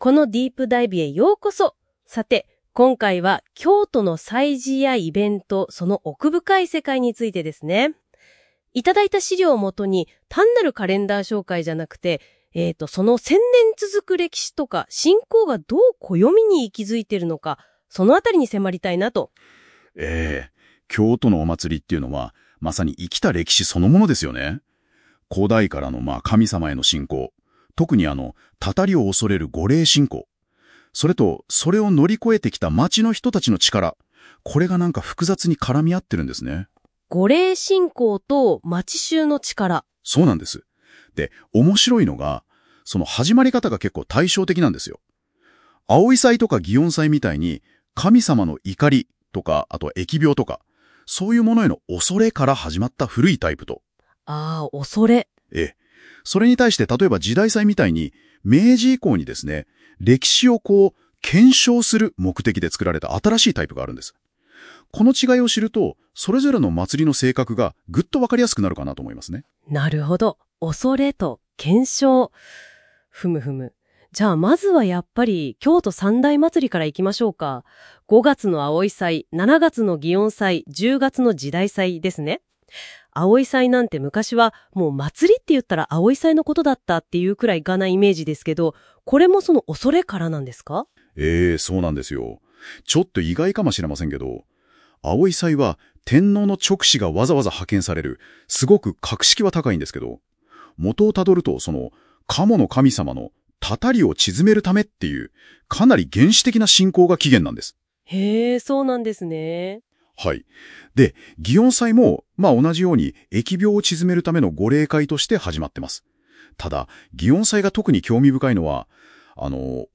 京都の地名は独特なので、ところどころ読み方が変になっていたりします。これは生成AIの限界ですね(笑)